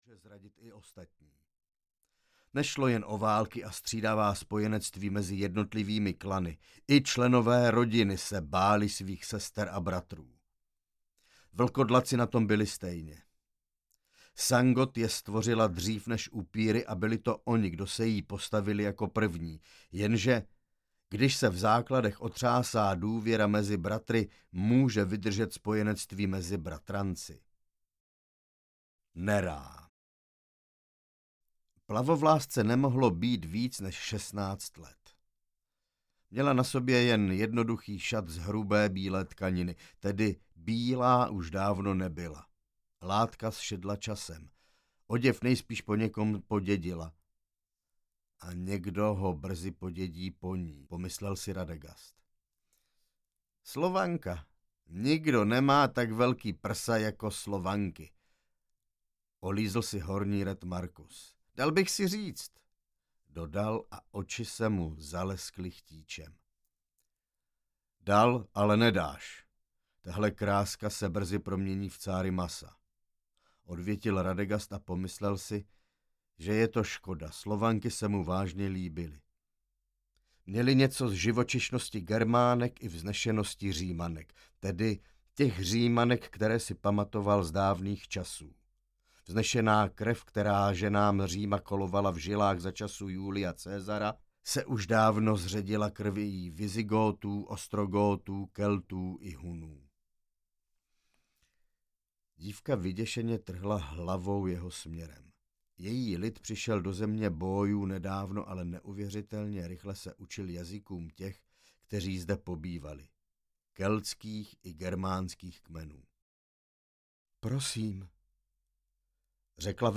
Vlci audiokniha
Ukázka z knihy